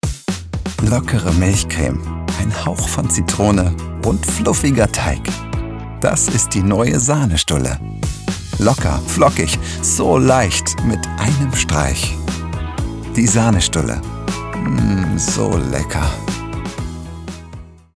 dunkel, sonor, souverän, plakativ
Mittel plus (35-65)
Werbung 04 - werblich sanft
Commercial (Werbung)